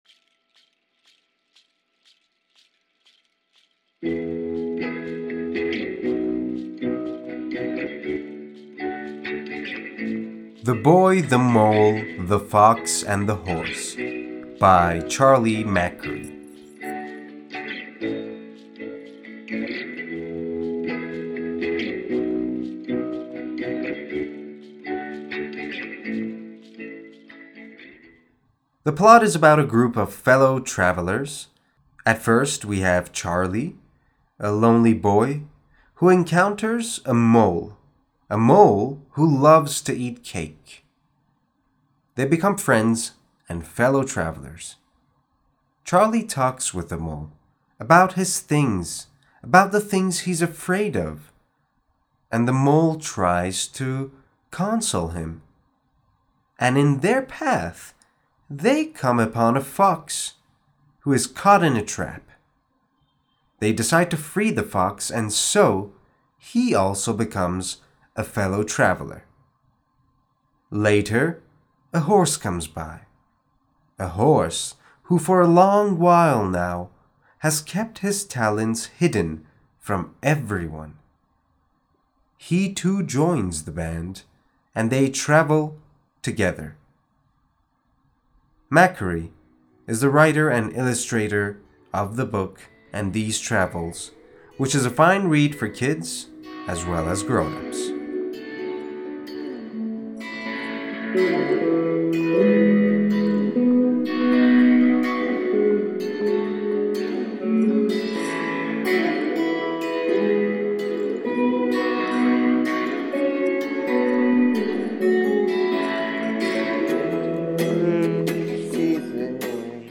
معرفی صوتی کتاب The Boy the Mole the Fox and the Horse